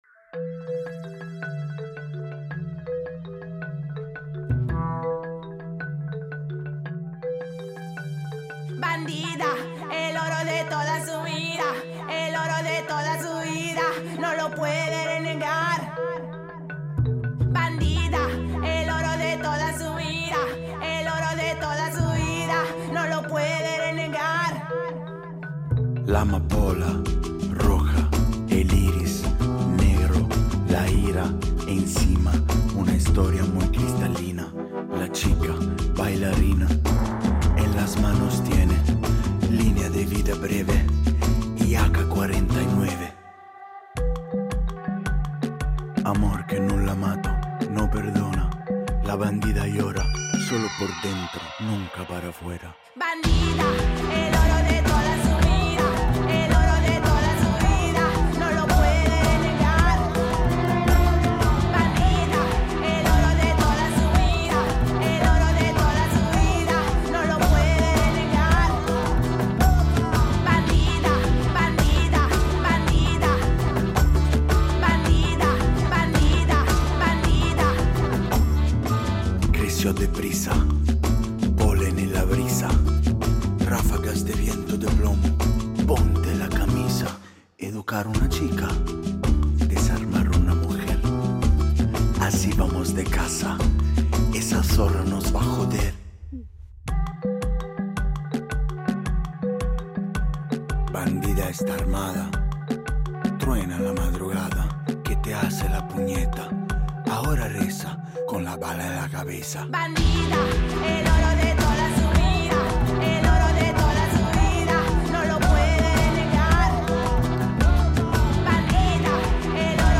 Intervista a Alessandro Mannarino